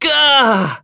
Esker-Vox_Damage_03.wav